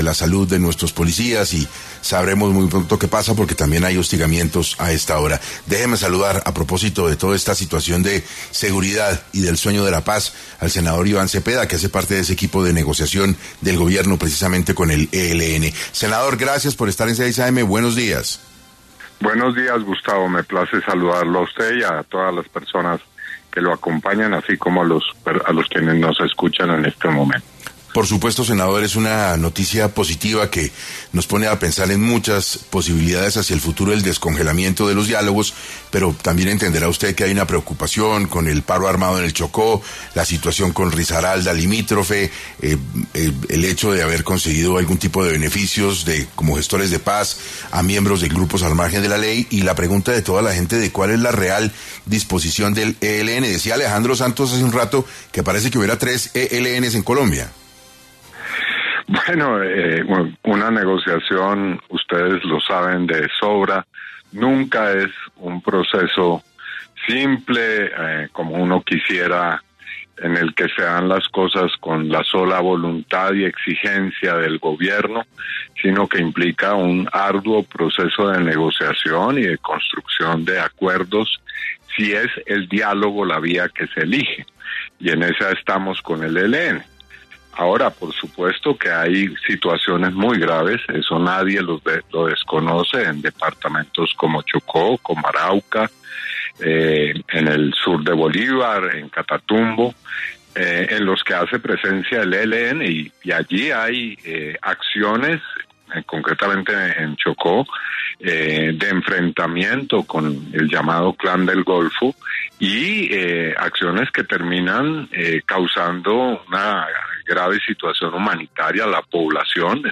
En diálogo con 6AM Iván Cepeda, integrante de la delegación del Gobierno en los diálogos con el ELN, manifestó que el proceso de paz con este grupo guerrillero no es tarea fácil y no se da solo por la voluntad de paz de las partes o exigencias del Gobierno Nacional: